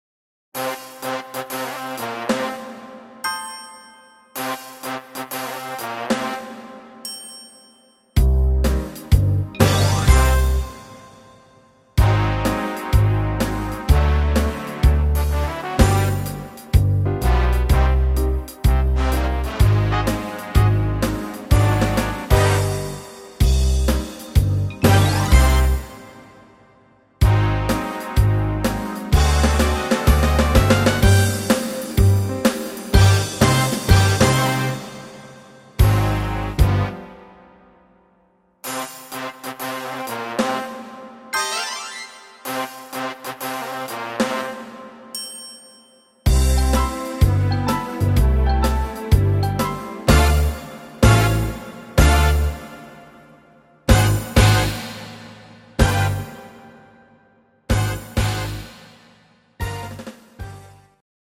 Rhythmus  Swing
Art  Jazz, Mega Hits, Weibliche Interpreten